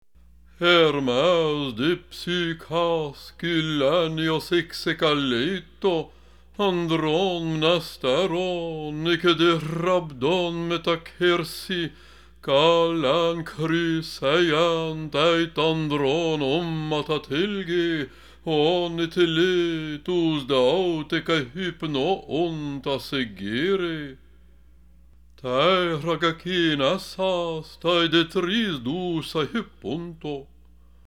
(unabridged, downloadable as single books, or all 24 books available on a single download)
The readings, available as MP3s, incorporate the restored historical pronunciation of Latin and Greek, following the scholarly conclusions of linguists and metricians.